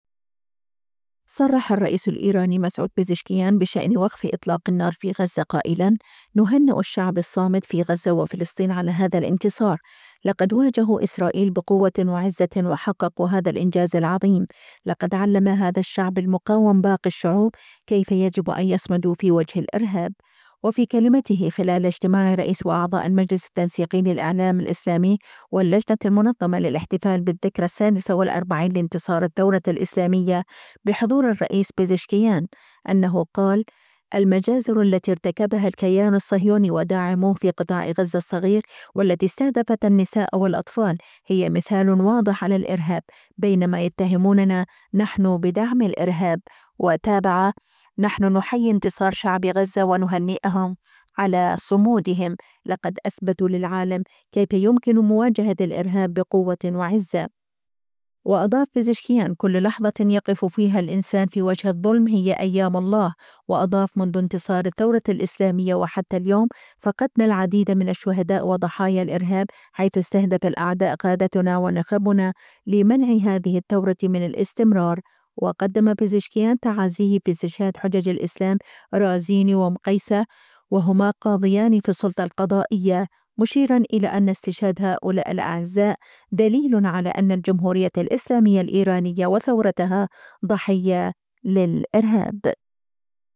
صرّح الرئيس الإيراني مسعود بزشكيان بشأن وقف إطلاق النار في غزة قائلاً: "نهنئ الشعب الصامد في غزة وفلسطين على هذا الانتصار. لقد واجهوا إسرائيل بقوة وعزة وحققوا هذا الإنجاز العظيم. لقد علّم هذا الشعب المقاوم باقي الشعوب كيف يجب أن يصمدوا في وجه الإرهاب".